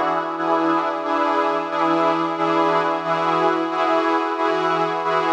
Index of /musicradar/sidechained-samples/90bpm